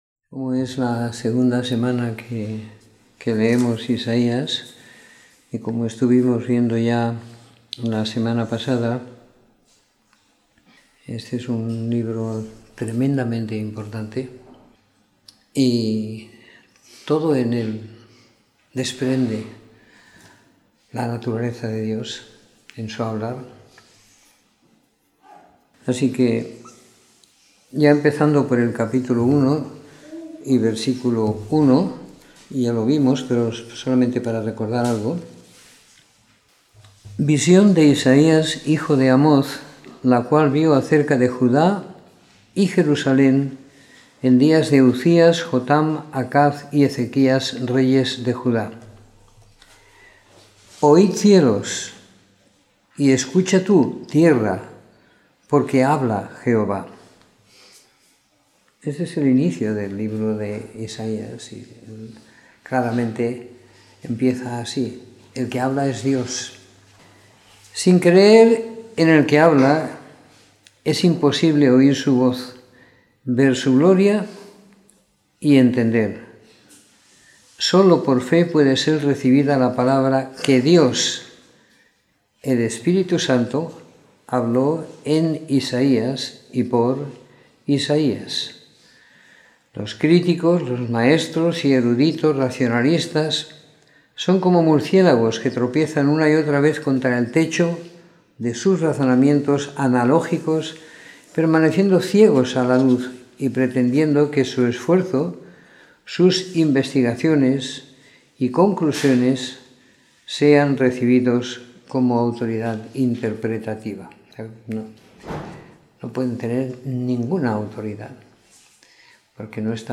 Comentario en el libro de Isaías del capítulo 36 al 66 siguiendo la lectura programada para cada semana del año que tenemos en la congregación en Sant Pere de Ribes.